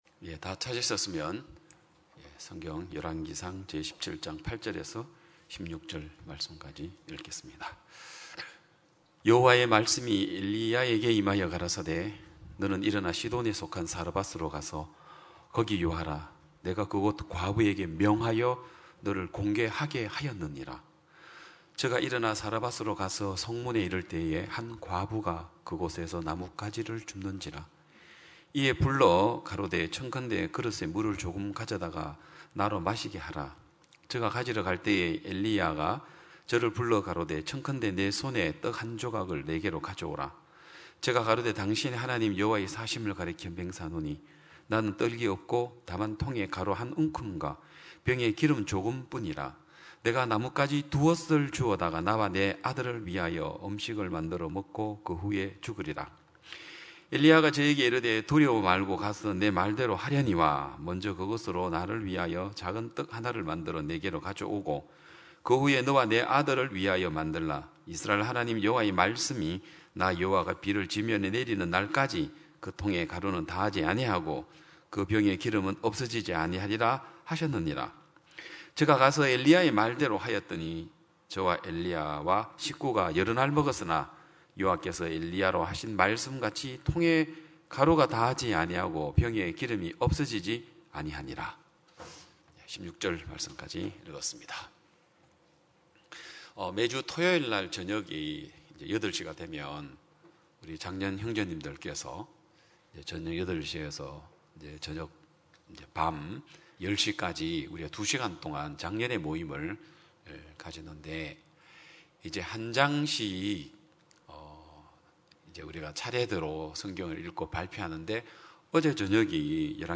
2022년 6월 26일 기쁜소식양천교회 주일오전예배
성도들이 모두 교회에 모여 말씀을 듣는 주일 예배의 설교는, 한 주간 우리 마음을 채웠던 생각을 내려두고 하나님의 말씀으로 가득 채우는 시간입니다.